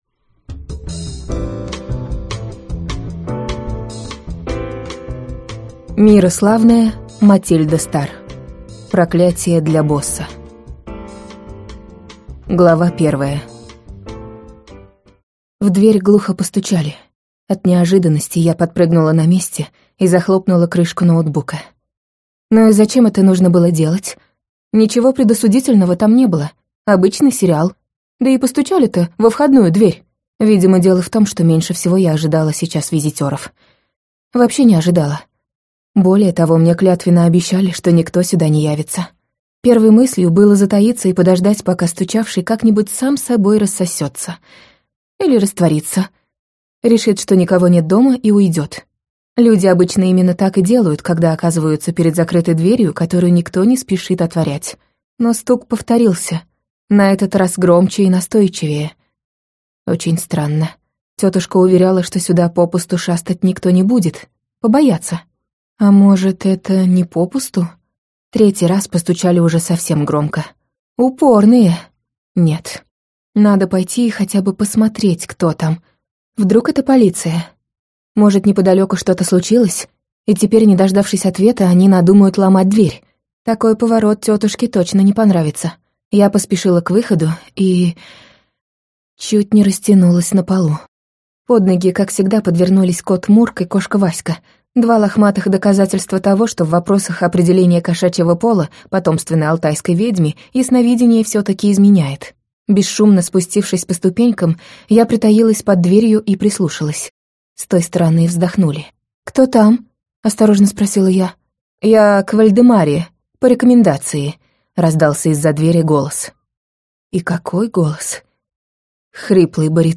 Аудиокнига Проклятие для босса | Библиотека аудиокниг
Прослушать и бесплатно скачать фрагмент аудиокниги